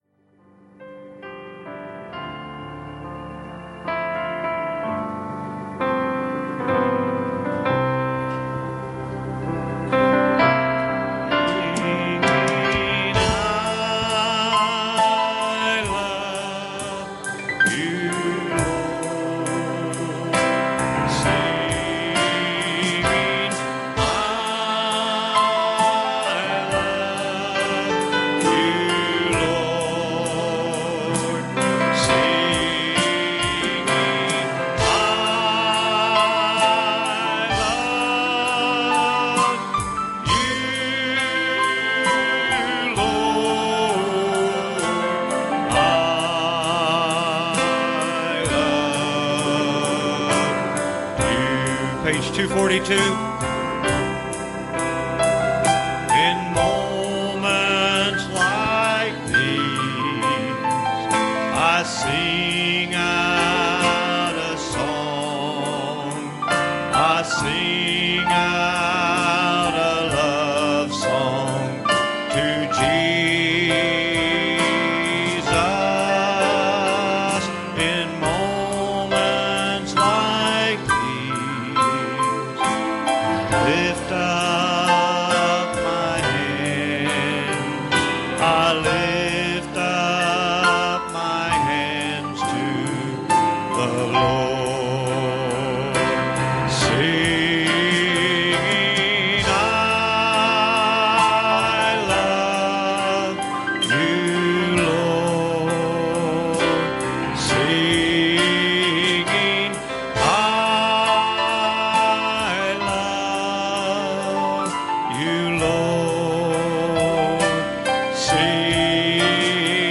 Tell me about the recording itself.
Passage: John 14:8 Service Type: Wednesday Evening